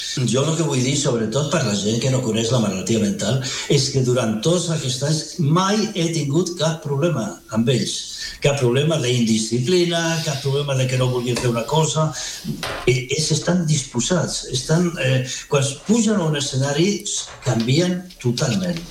Aquest dijous hem conversat amb ell a l’Entrevista del dia del matinal de RCT, on ha recordat els orígens del projecte i una experiència especialment colpidora que exemplifica el poder de la música.